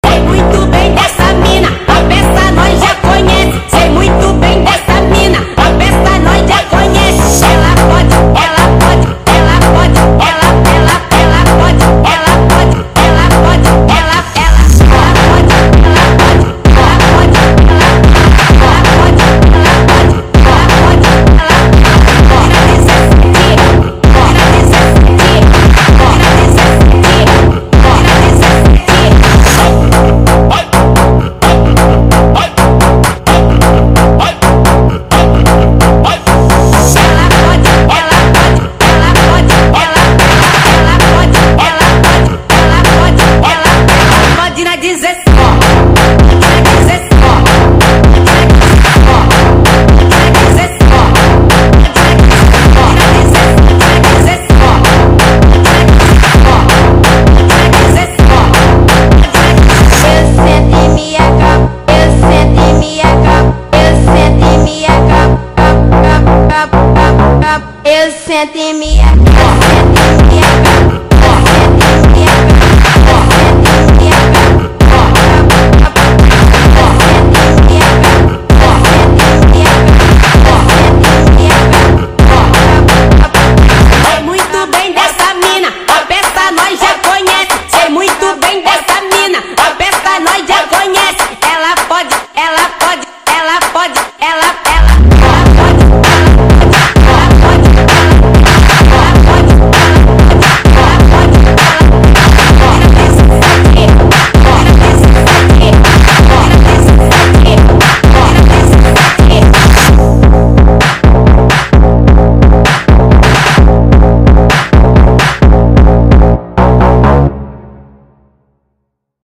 فانک